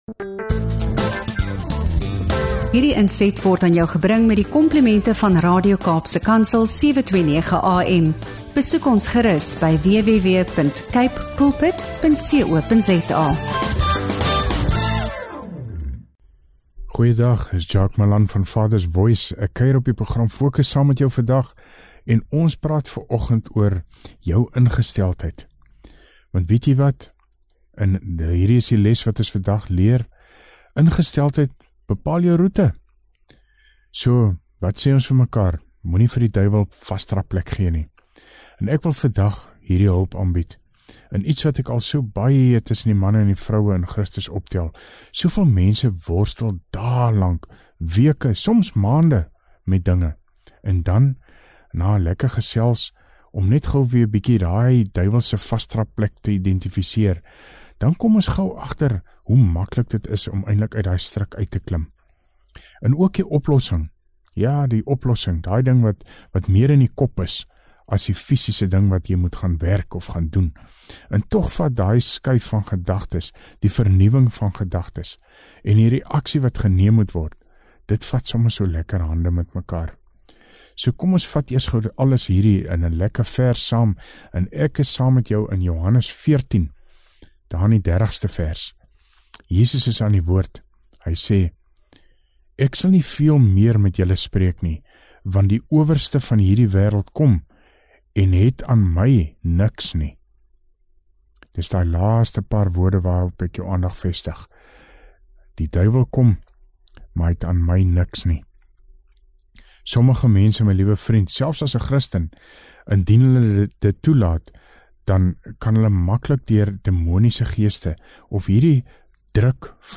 Deur insigte uit Johannes 14 en Paulus se boodskap leer hy hoe om negatiewe denke, angs en stres te oorwin, en hoe om geen voetsool vir die vyand te gee nie. ŉ Praktiese en bemoedigende gesprek oor gedagtes, reaksies en geestelike oorwinning.